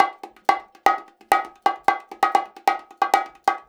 130BONGO 07.wav